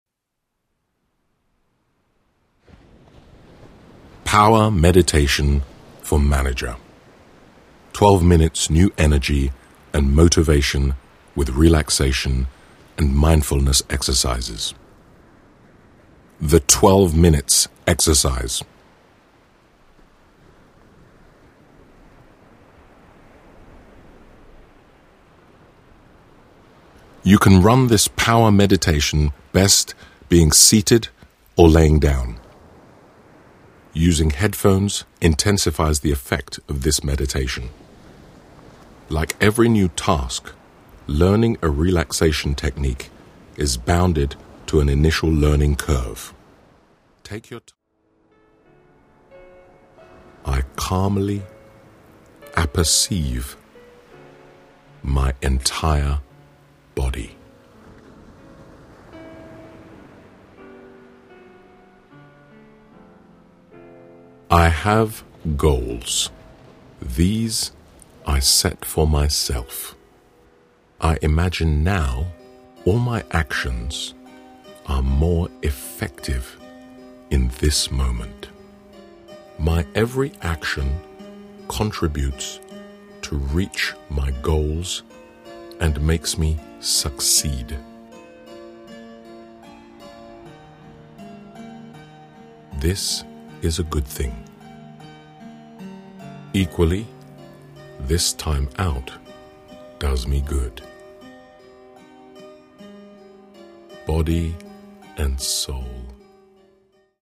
Modules in this audiobook:
The "Power Meditation for Managers" is set to stimulating (neo-)classical music, which particularly induces brain activity, such as joined-up thinking and intuition, and generally animates mental vigor.